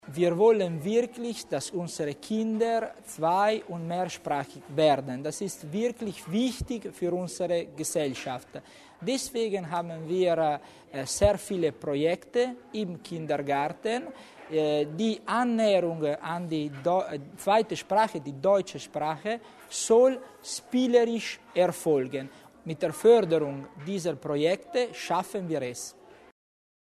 Landesrat Tommasini über die Wichtigkeit des frühzeitigen Spracherwerbs